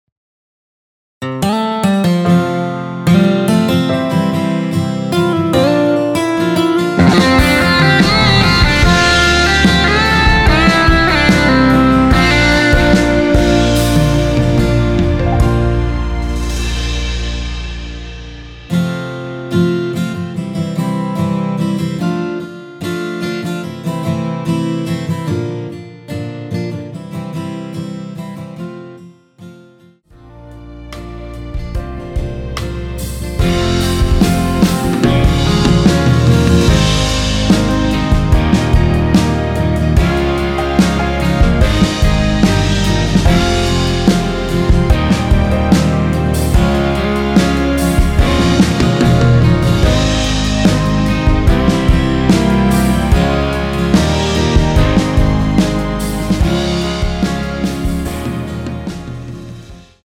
원키에서(-2)내린 MR입니다.
앞부분30초, 뒷부분30초씩 편집해서 올려 드리고 있습니다.